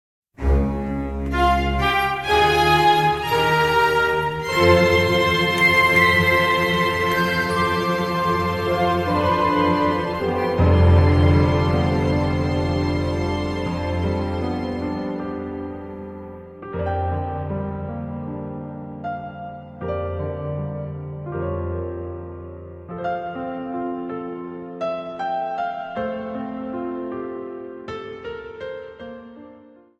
Music
Fair use music sample
Applied fade-out